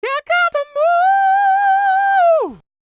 DD FALSET010.wav